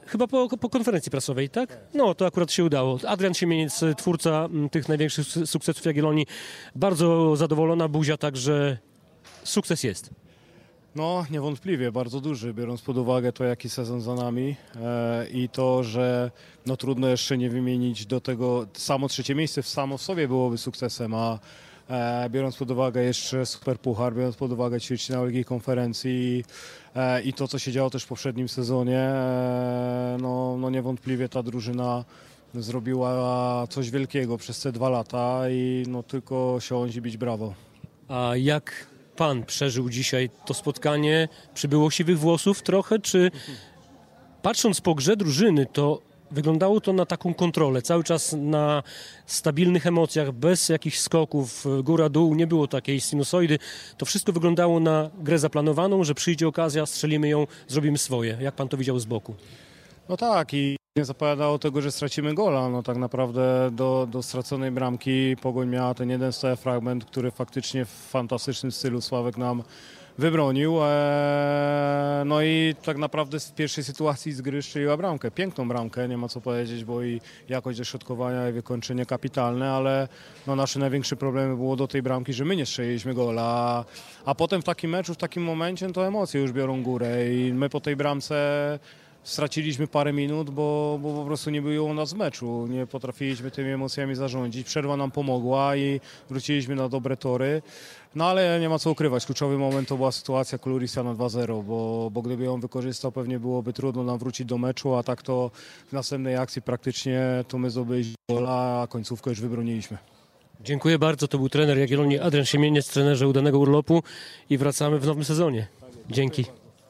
Adrian Siemieniec: Niewątpliwie ta drużyna zrobiła coś wielkiego przez dwa ostatnie lata. Trener Jagiellonii o sezonie i meczu | Pobierz plik.